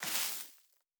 added stepping sounds
Tall_Grass_Mono_01.wav